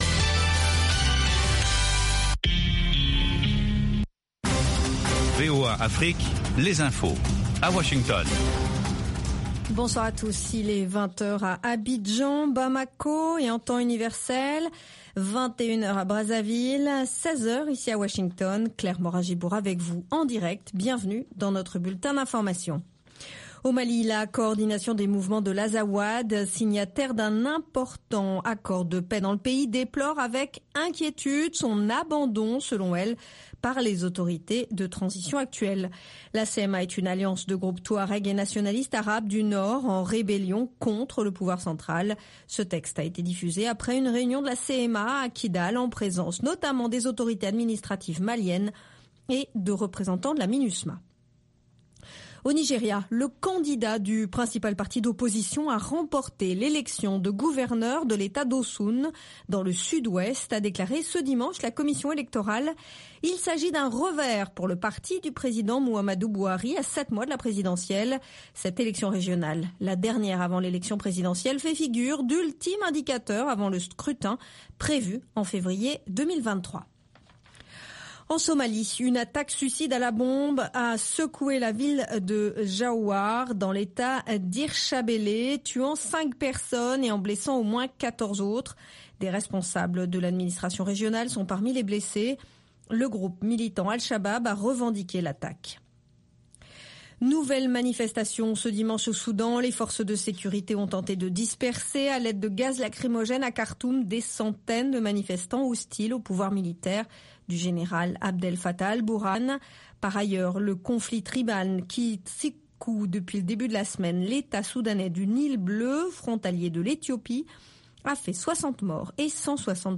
RM Show - French du blues au jazz